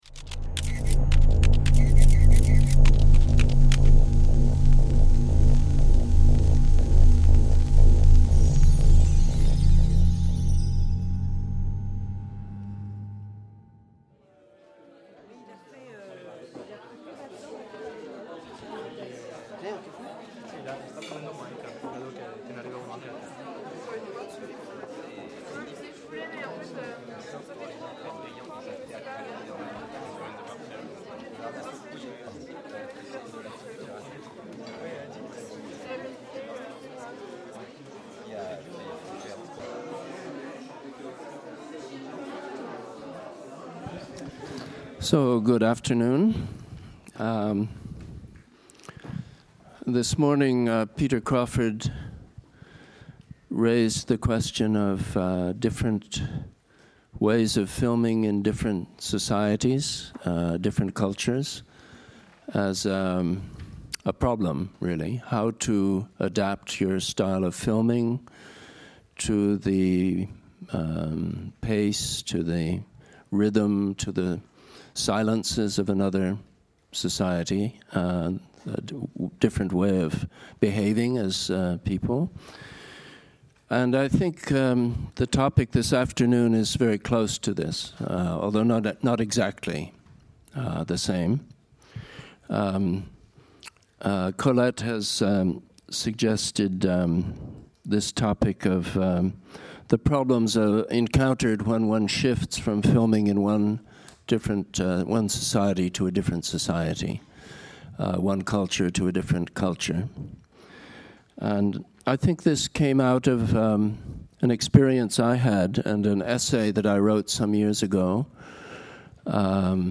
2 - Conférence (VO) avec extraits de films de David MacDougall | Canal U